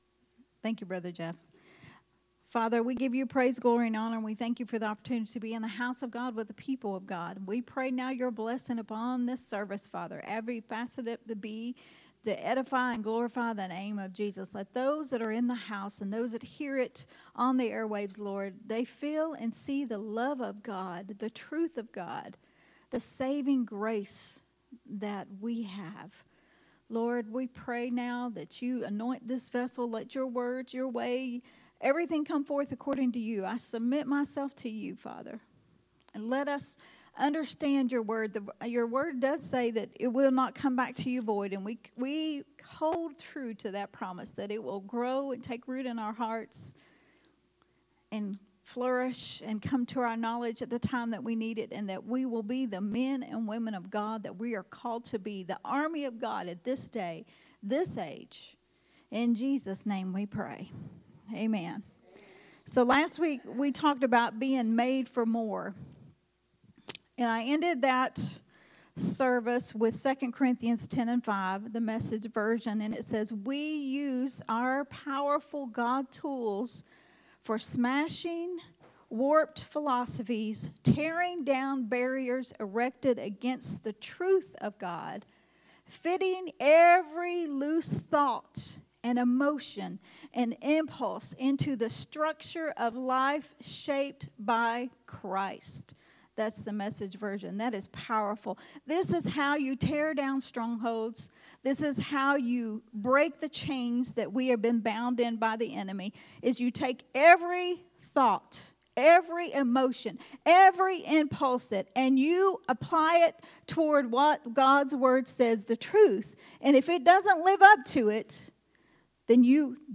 recorded at Unity Worship Center on January 28th, 2024.